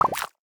UIClick_Bubbles Splash Twisted Vegetables 01.wav